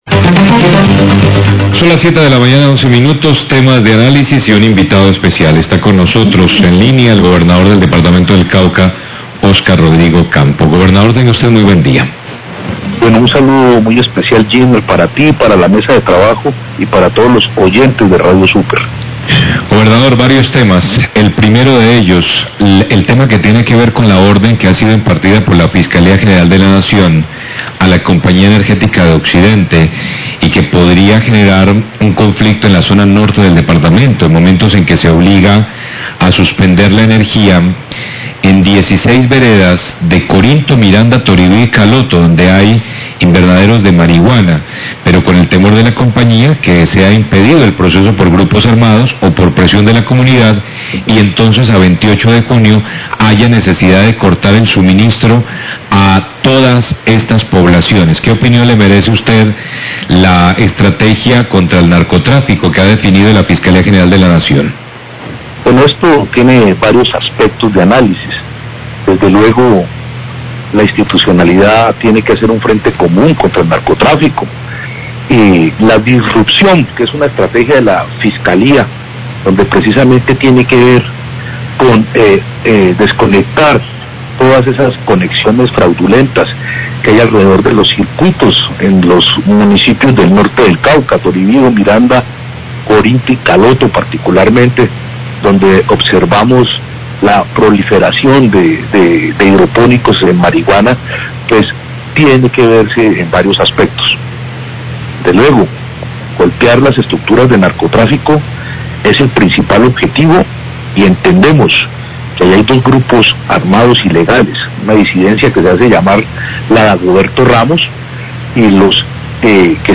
GOBERNADOR CAUCA HABLA DE ORDEN DE SUSPENSIÓN DE ENERGÍA EN NORTE DEL CAUCA
Radio